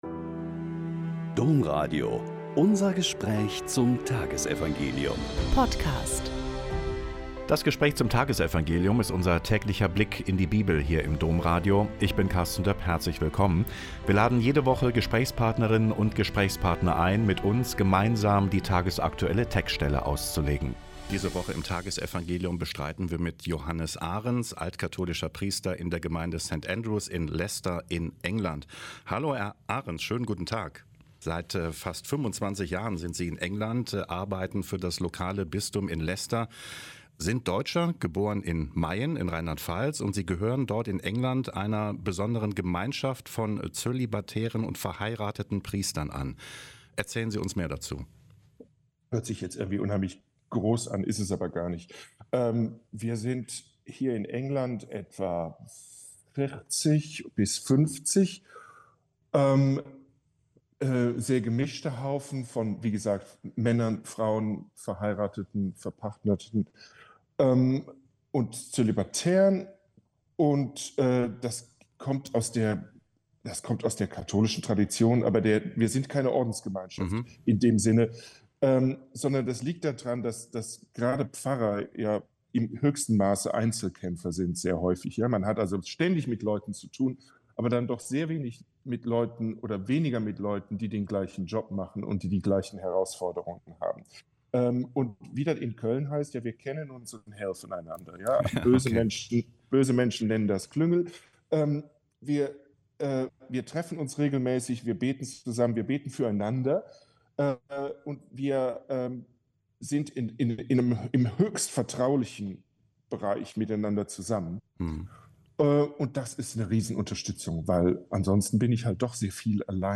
Mk 9,38-40 - Gespräch